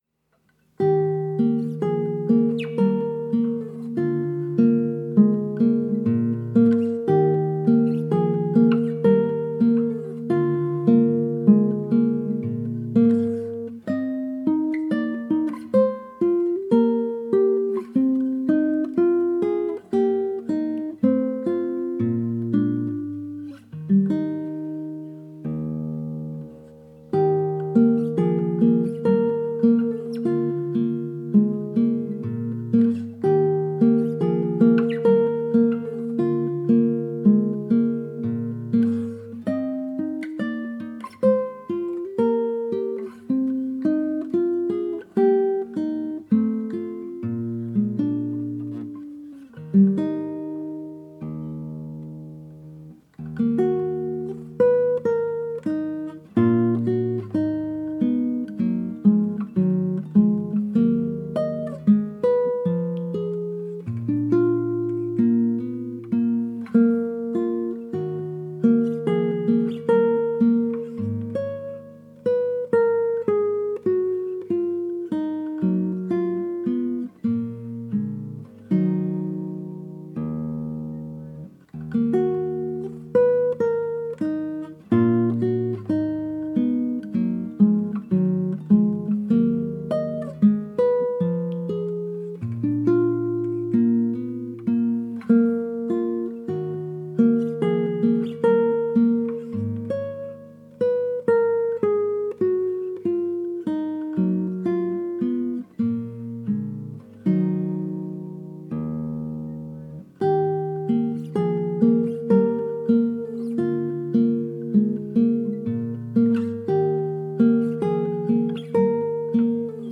A short extract from "Lagrima" by F. Tarrega, played on a Torres 1889 replica in Birdseye maple and European spruce.